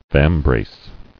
[vam·brace]